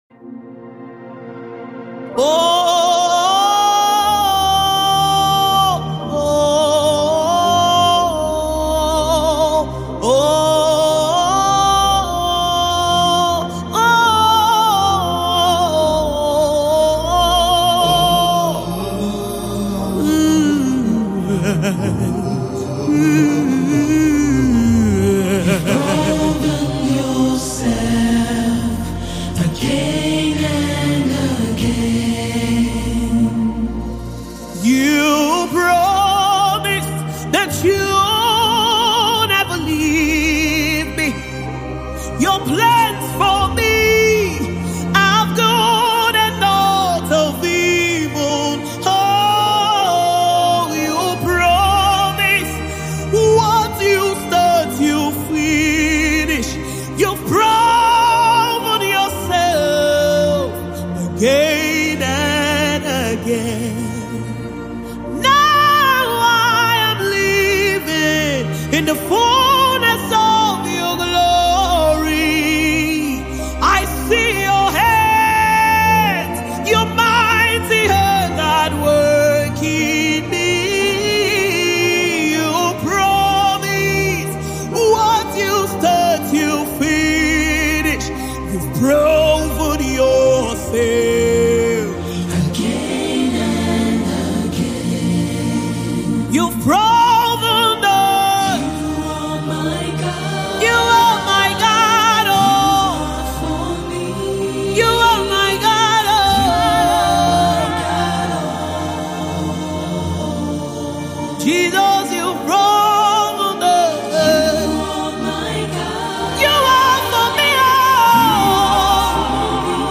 a soul-stirring gospel project with 10 powerful tracks
With her strong voice and heartfelt lyrics